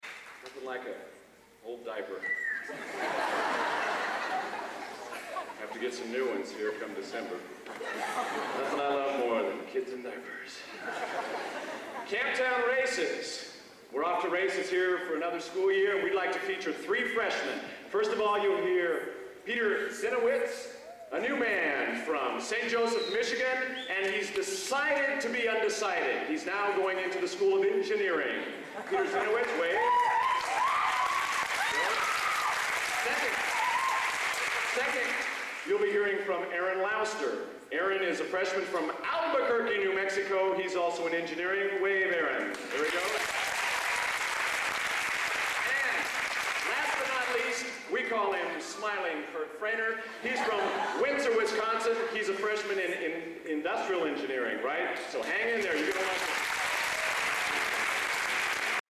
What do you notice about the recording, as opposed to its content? Location: Purdue Memorial Union, West Lafayette, Indiana